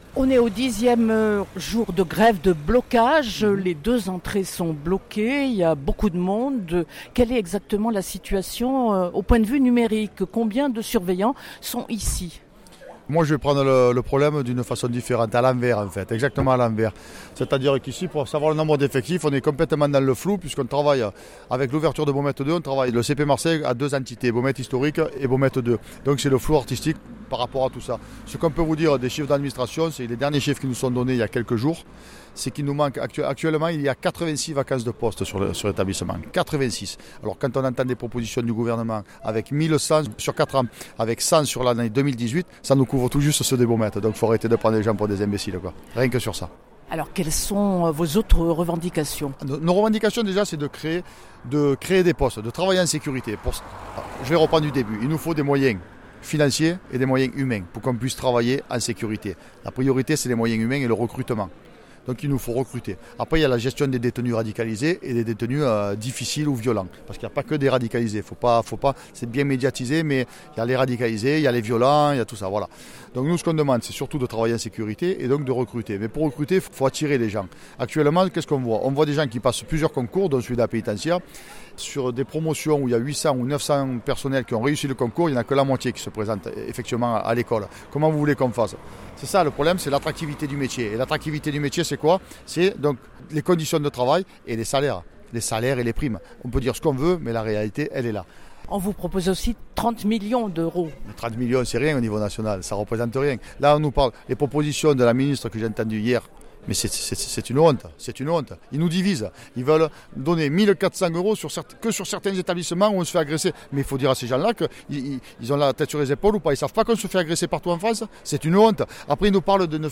Entretien…